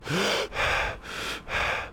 breath3.mp3